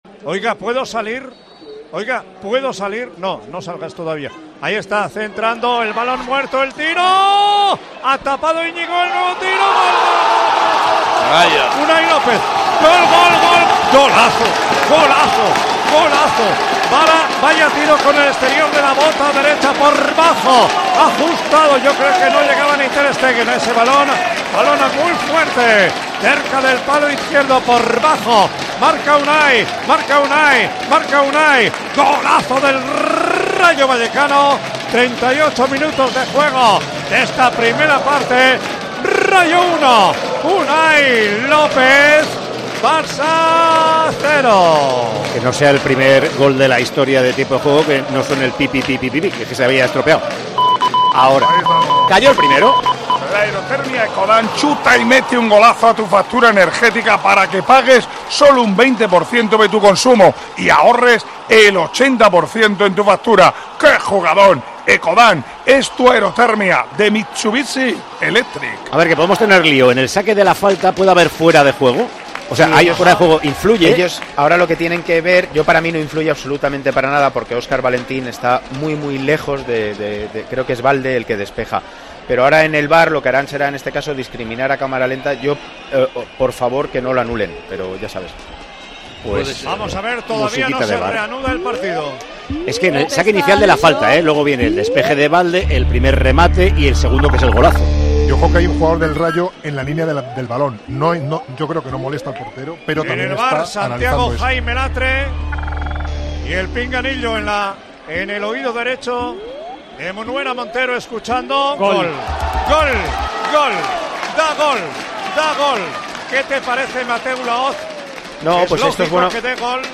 Comentado el citado encuentro para Tiempo de Juego estuvo el excolegiado internacional Antonio Mateu Lahoz que analizó así la polémica arbitral del choque de Vallecas.